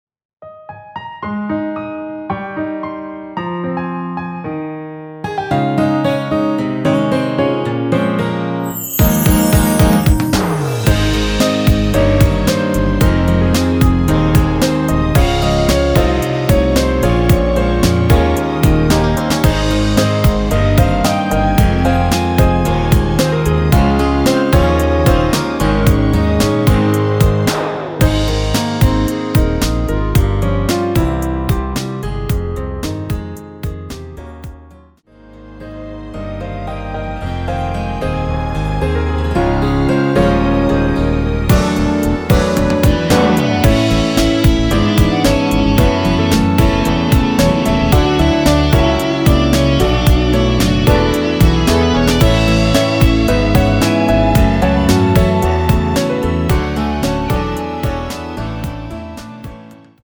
원키에서(-3)내린 멜로디 포함된 MR입니다.(미리듣기 확인)
앞부분30초, 뒷부분30초씩 편집해서 올려 드리고 있습니다.
중간에 음이 끈어지고 다시 나오는 이유는